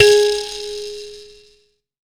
Index of /90_sSampleCDs/AKAI S6000 CD-ROM - Volume 5/Africa/KALIMBA